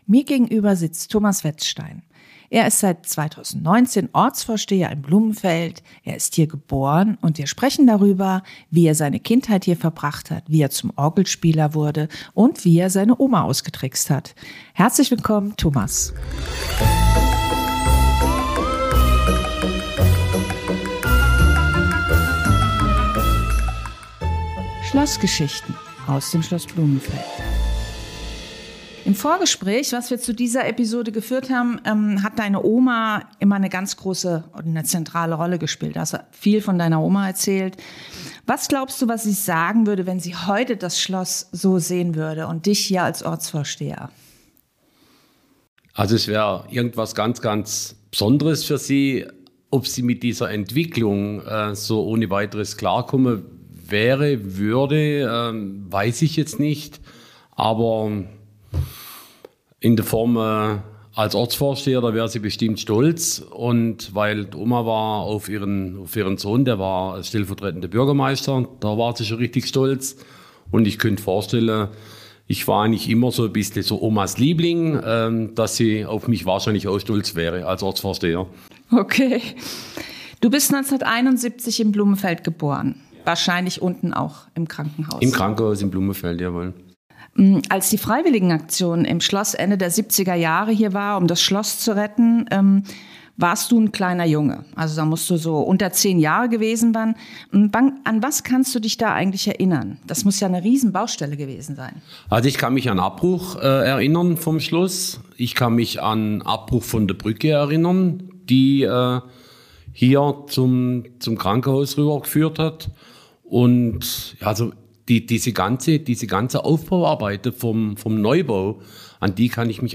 Interview mit Thomas Wezstein - seit 2019 Ortsvorsteher in Blumenfeld ~ Schlossgeschichten aus Schloss Blumenfeld Podcast